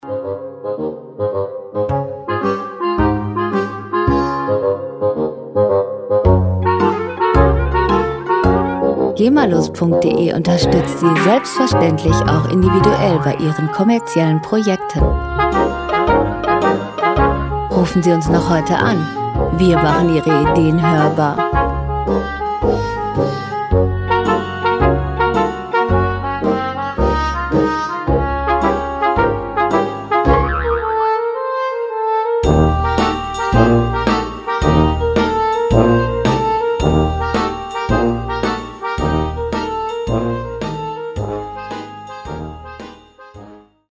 Hintergrundmusik - Quiz und Shows
Musikstil: Cartoon Music
Tempo: 110 bpm
Tonart: Des-Dur
Charakter: witzig, Träge